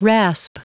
Prononciation du mot rasp en anglais (fichier audio)
Prononciation du mot : rasp